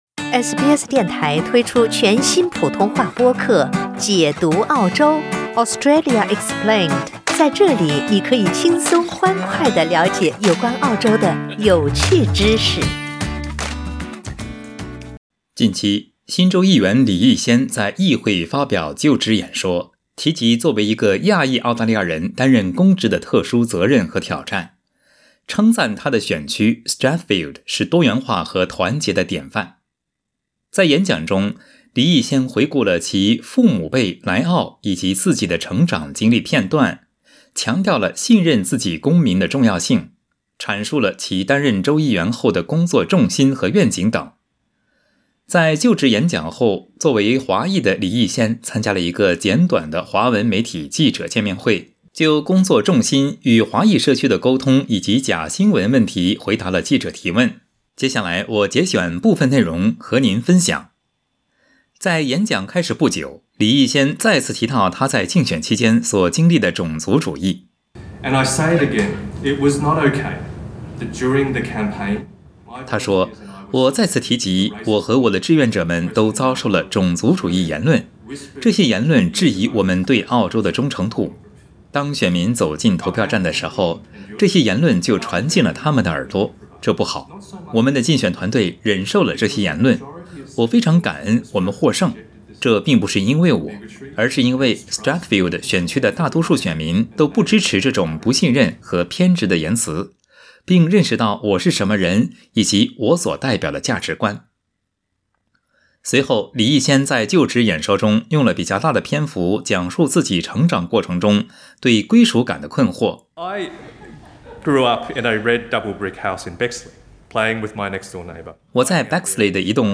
Jason Yat-sen Li made his inaugural speech to NSW Parliament on Wednesday March 23, 2022 Source